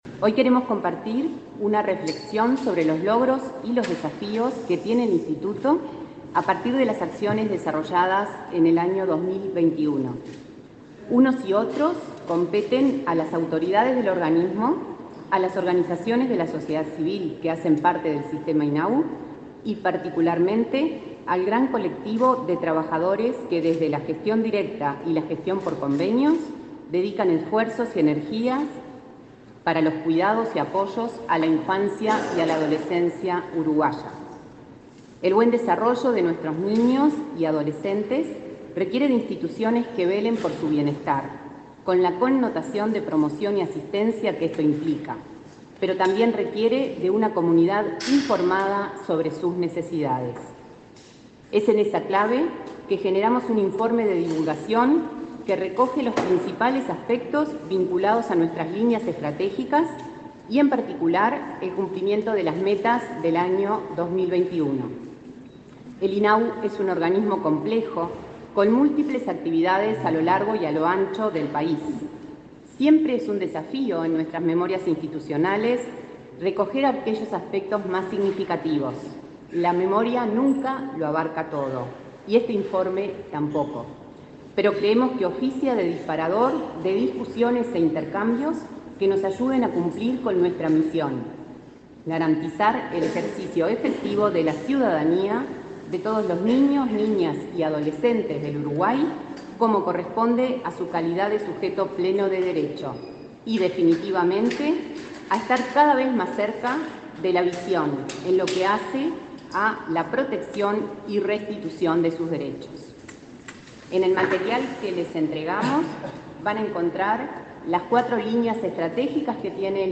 Palabras de autoridades en rendición de cuentas 2021 de INAU